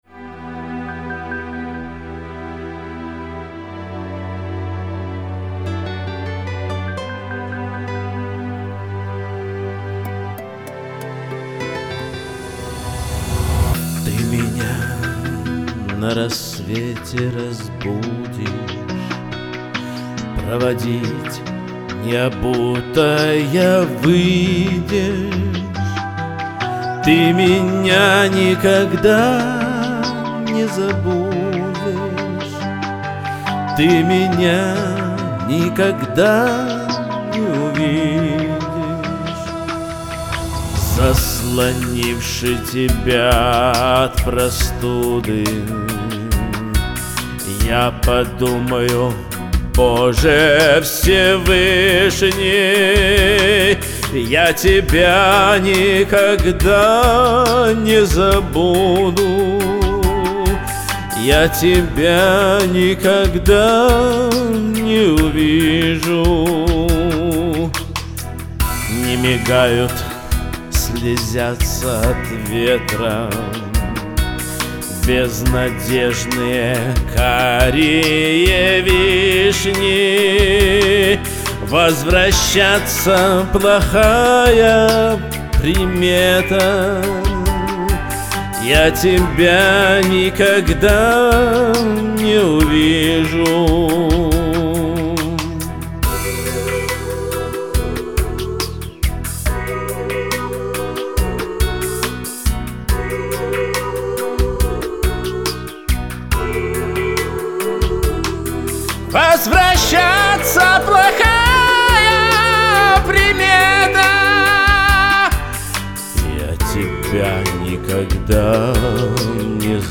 У голосов один возраст...
Исполнения разные, голос один.
Конечно, первый- более эмоционально спет, с надрывом, ярче.